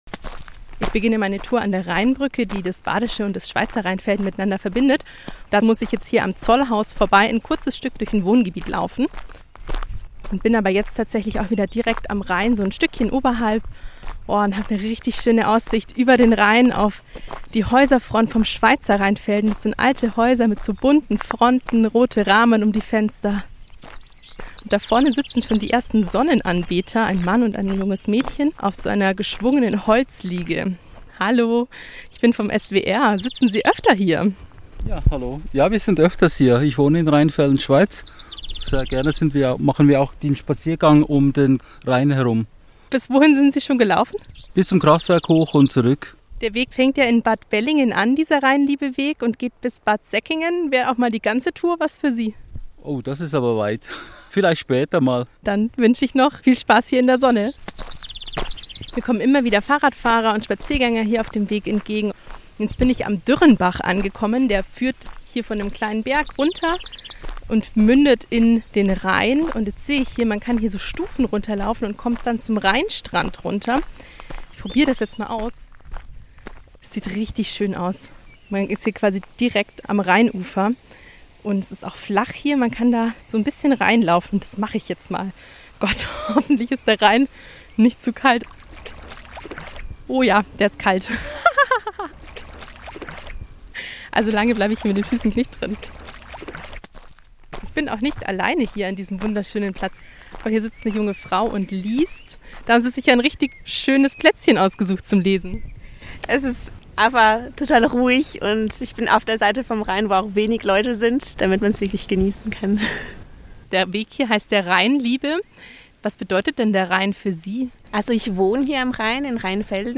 Die Sonne scheint warm in das Gesicht, die Vögel zwitschern. Der Rhein fließt träge und ruhig vorbei.